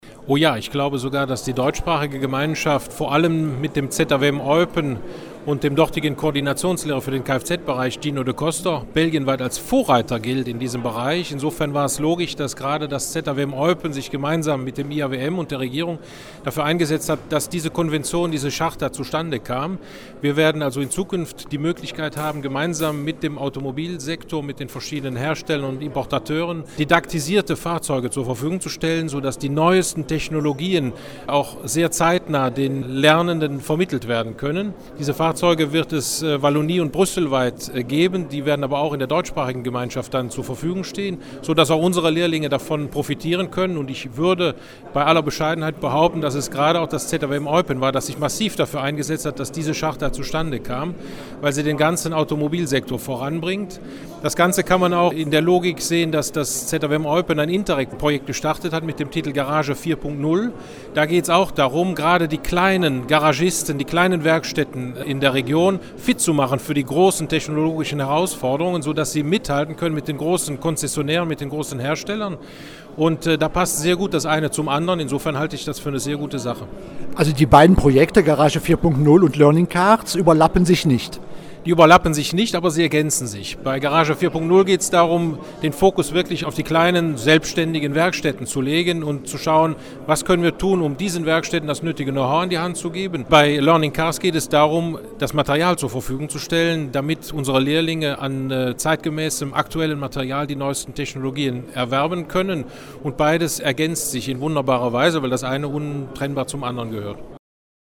Minister Harald Mollers gefragt, ob Ostbelgien eine bedeutende Rolle in diesem Projekt spielt: